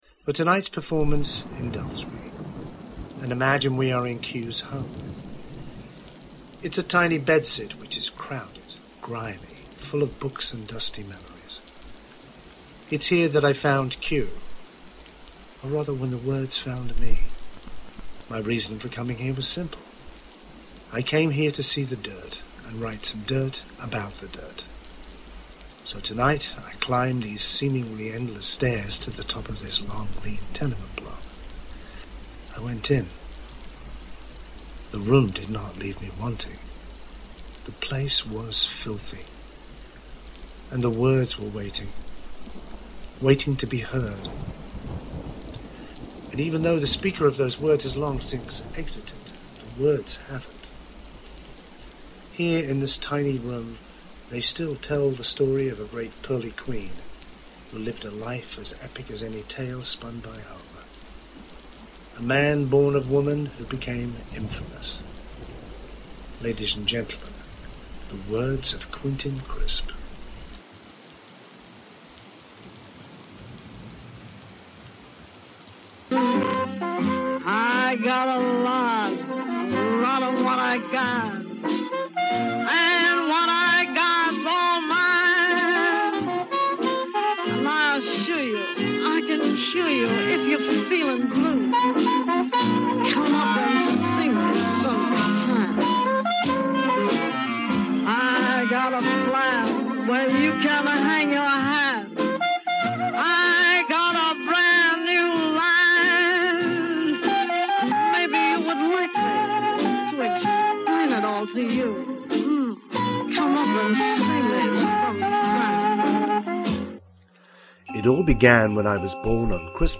A recording of myself performing ‘Channeling Quentin Crisp’